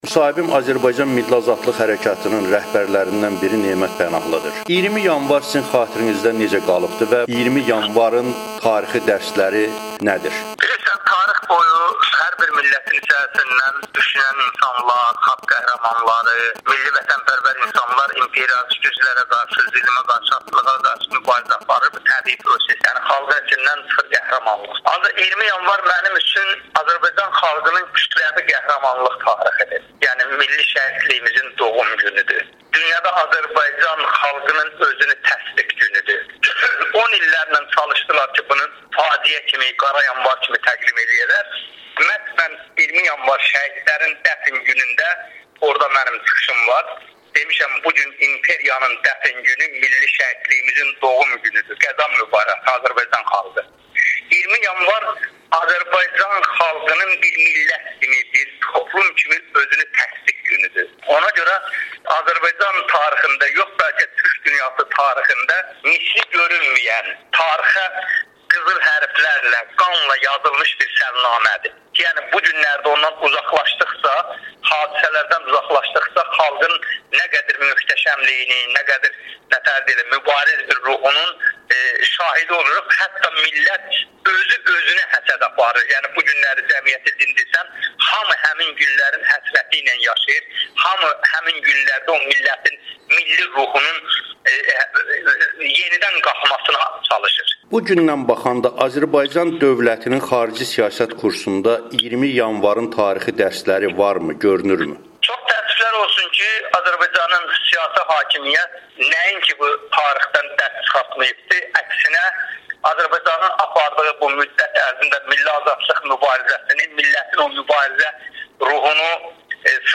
müsahibəsi